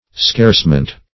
Search Result for " scarcement" : The Collaborative International Dictionary of English v.0.48: Scarcement \Scarce"ment\, n. (Arch.